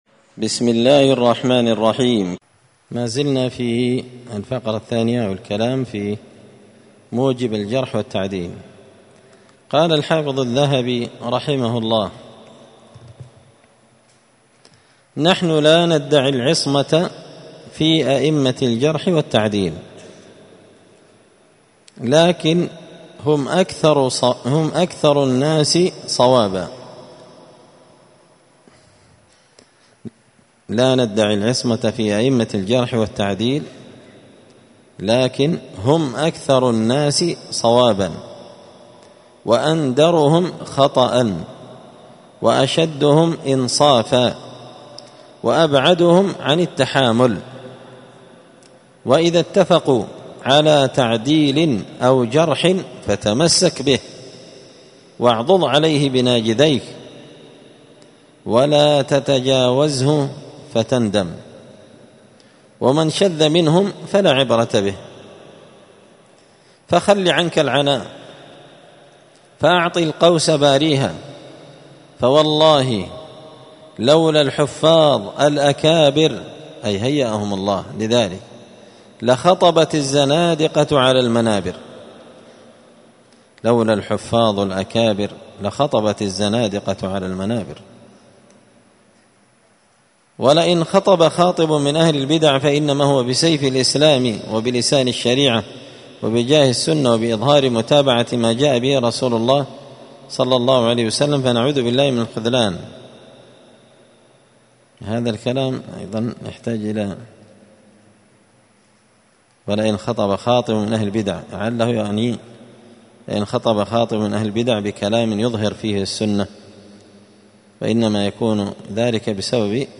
الخميس 11 ربيع الثاني 1445 هــــ | الدروس، المحرر في الجرح والتعديل، دروس الحديث وعلومه | شارك بتعليقك | 72 المشاهدات
مسجد الفرقان قشن_المهرة_اليمن